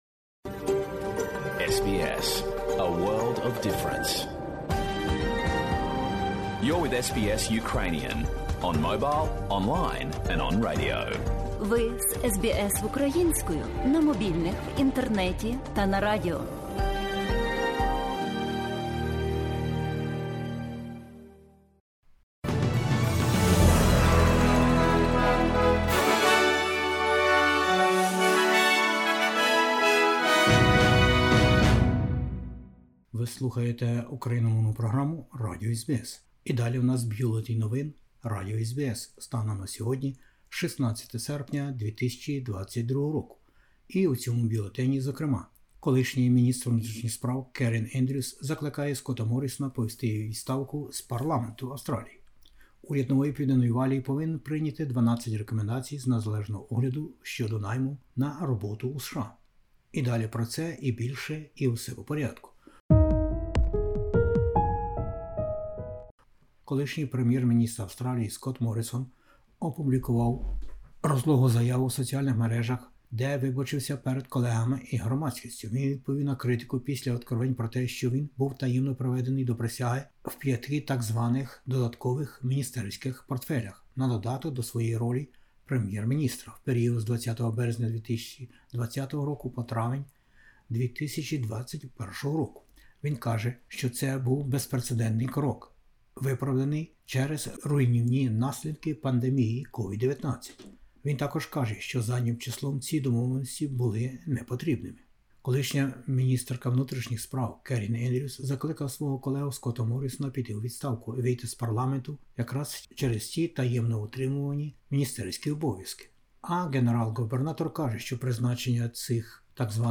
SBS News in Ukrainian - 16/08/2022